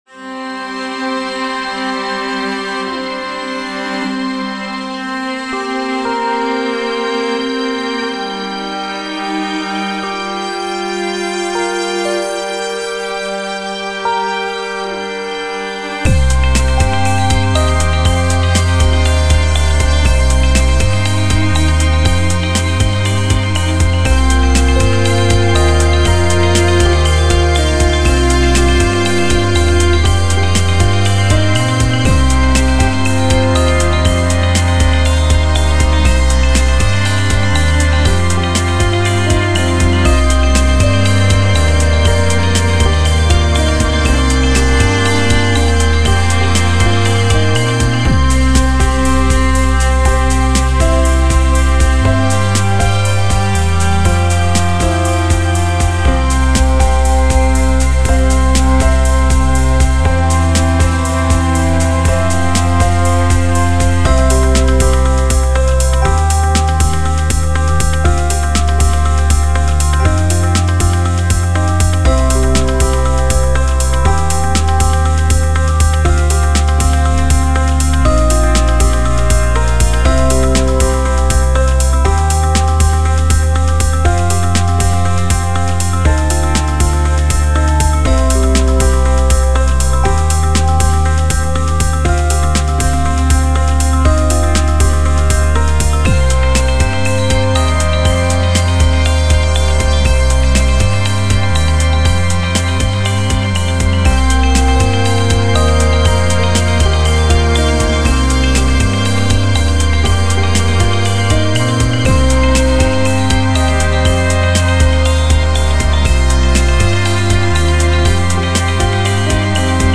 Falling (reflective, patient, content)